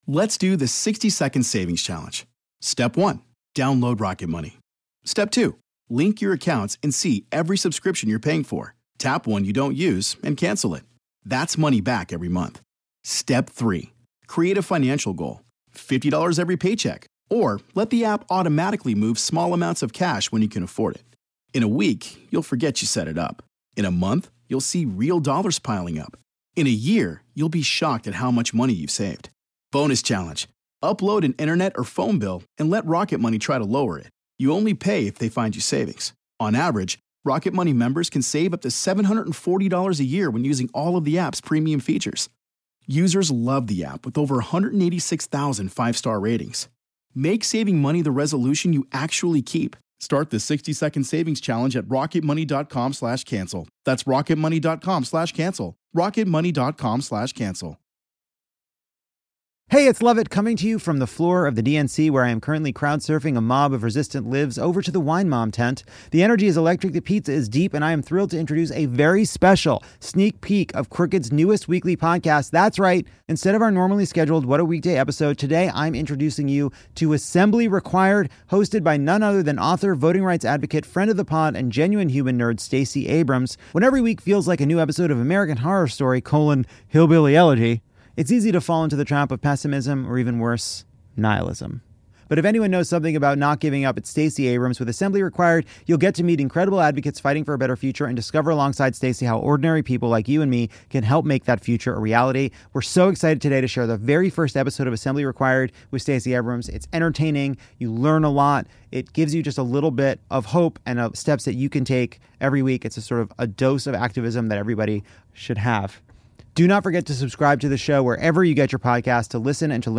The election is in the hands of Gen Z, a cohort of young Americans that’s diverse, creative, and—often—skeptical. This episode at the Democratic National Convention in Chicago, Stacey is joined by Rep.